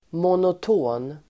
Uttal: [monot'å:n]